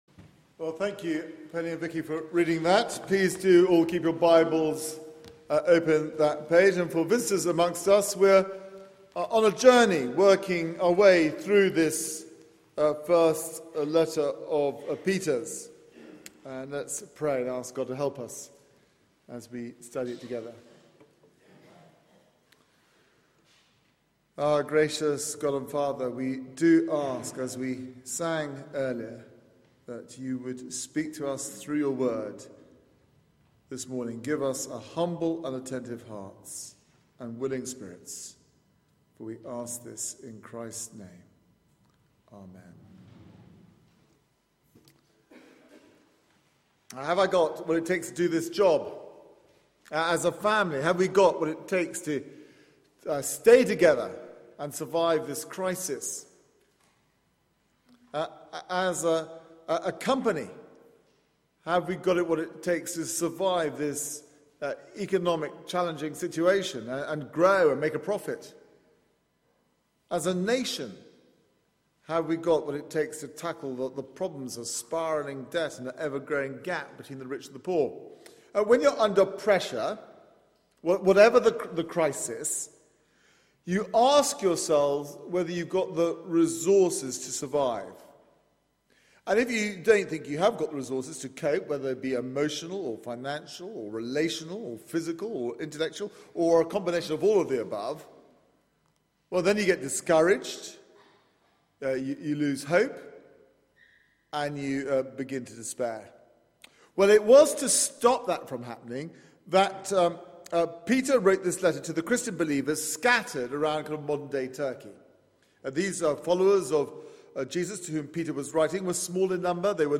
Media for 9:15am Service on Sun 09th Feb 2014
Sermon